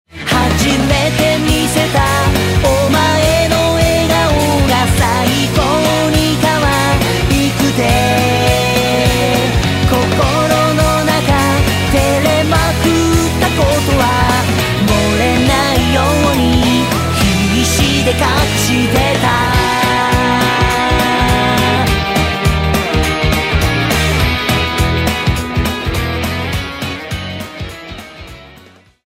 キャラクターイメージソング